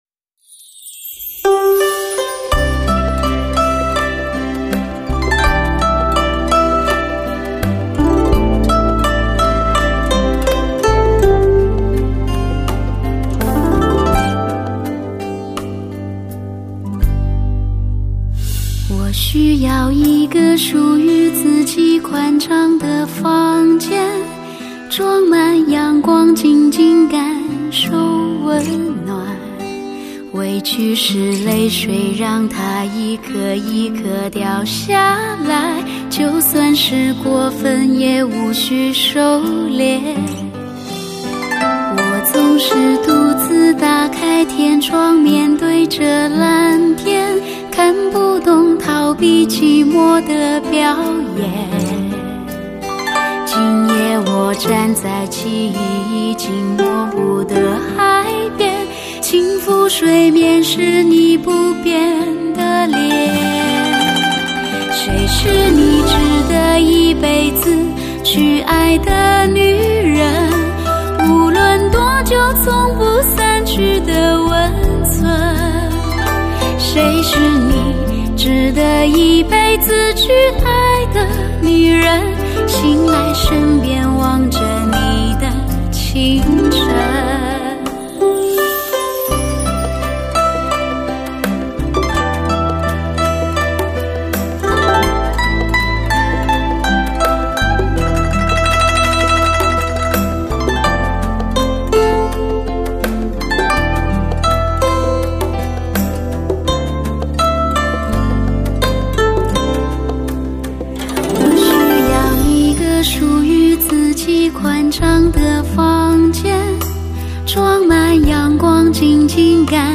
唱片类型：汽车音乐
高临场感CD。